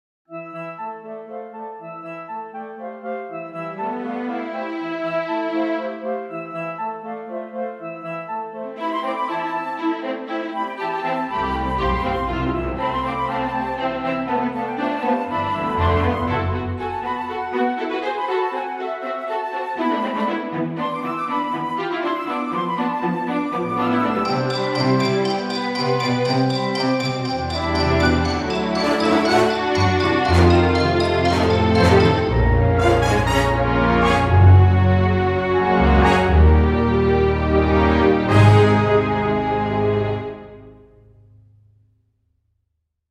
Here's an improved version with less muddy overlaps and chaos.